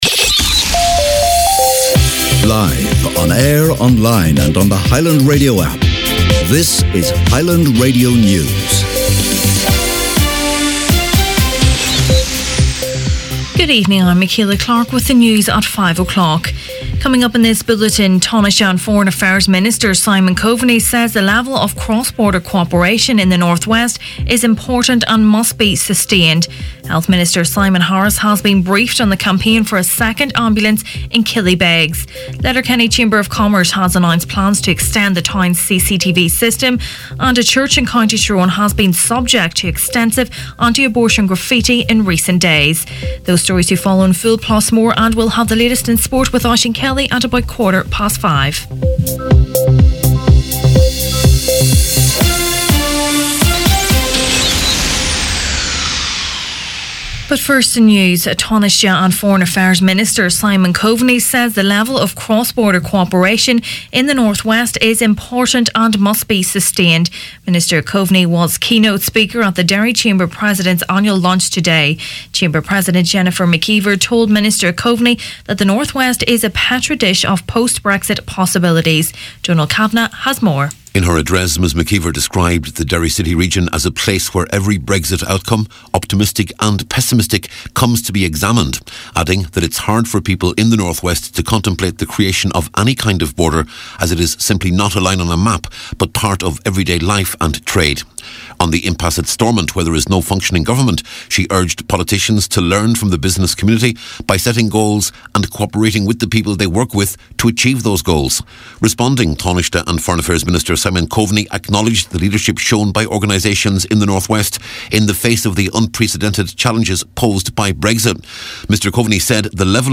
Main Evening News, Sport and Obituaries Wednesday 25th April